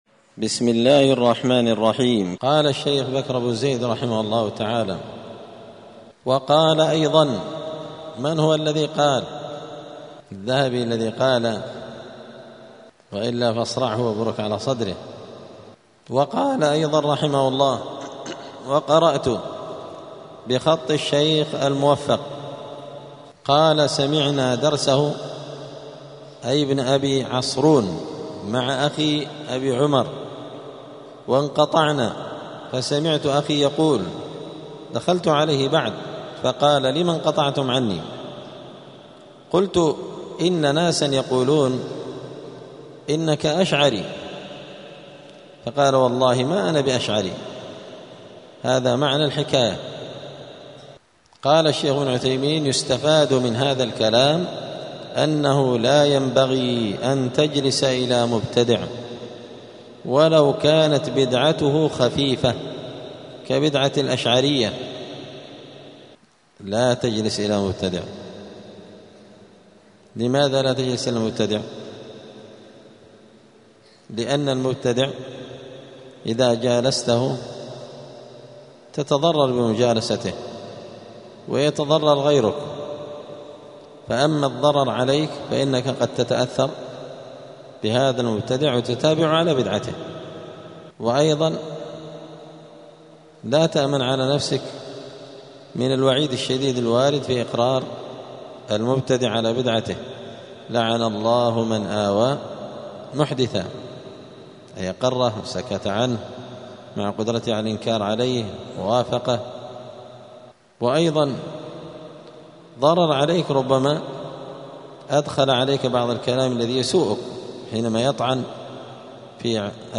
*الدرس التاسع والثلاثون (39) {فصل التلقي عن المبتدع}*